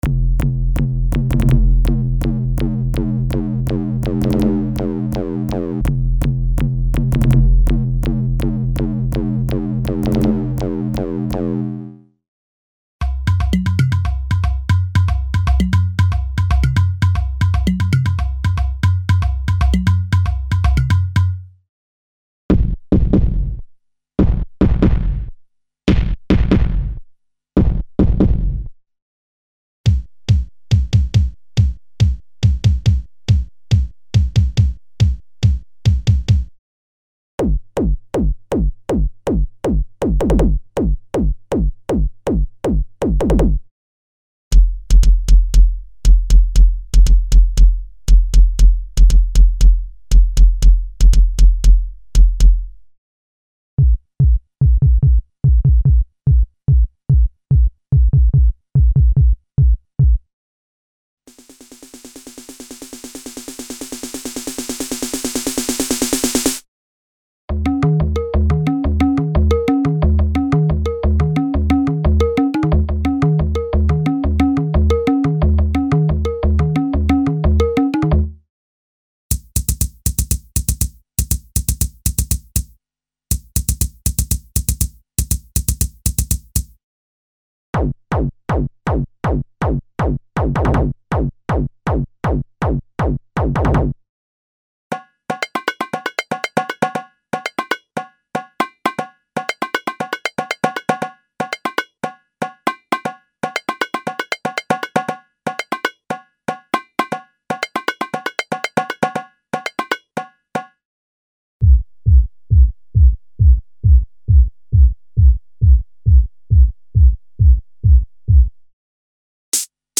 Acoustic and electronic single drum programs for various music styles, including special techno kick & snare programs never before released for Kurzweil K2xxx synthesizers.
Info: All original K:Works sound programs use internal Kurzweil K2500 ROM samples exclusively, there are no external samples used.
K-Works - Drums & Percussion Volume 1 - EX (Kurzweil K2xxx).mp3